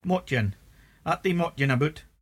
[mOHT-yen: at thee mOHTyen aboot]